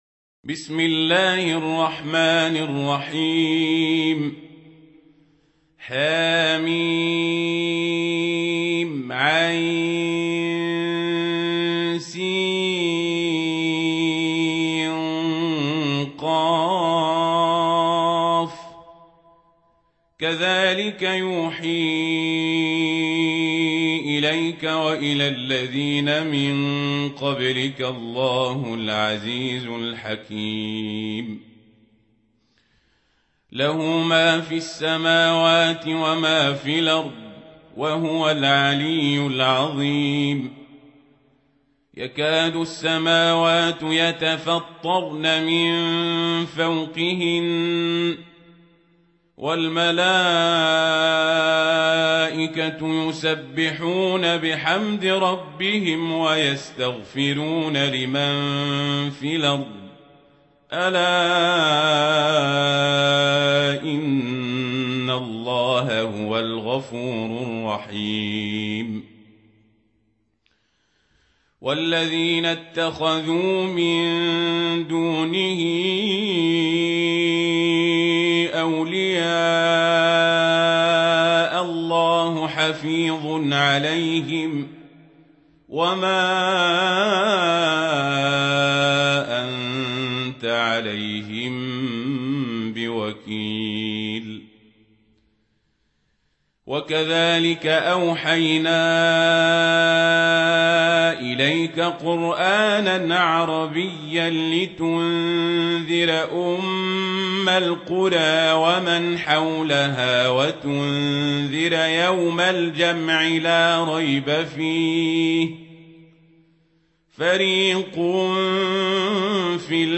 سورة الشورى | القارئ عمر القزابري